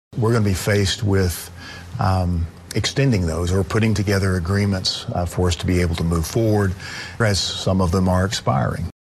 CLICK HERE to listen to commentary from House Speaker Charles McCall.